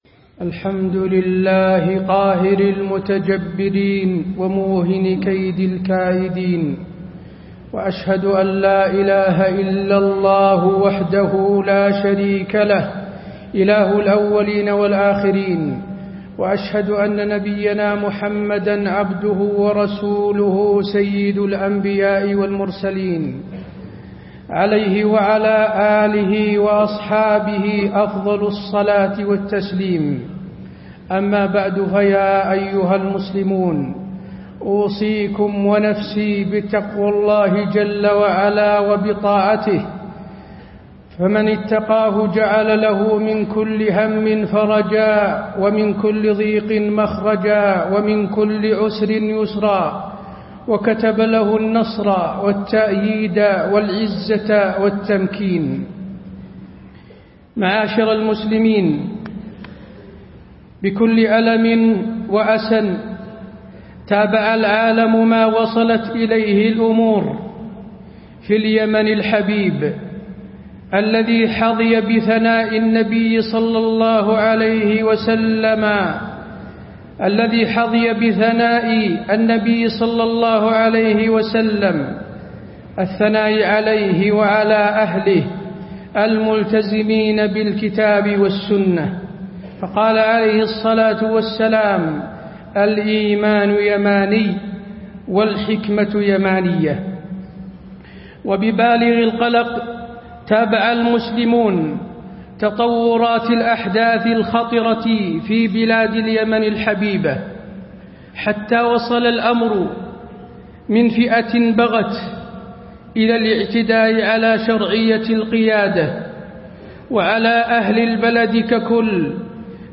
تاريخ النشر ٧ جمادى الآخرة ١٤٣٦ هـ المكان: المسجد النبوي الشيخ: فضيلة الشيخ د. حسين بن عبدالعزيز آل الشيخ فضيلة الشيخ د. حسين بن عبدالعزيز آل الشيخ حقوق الأخوة وأحداث اليمن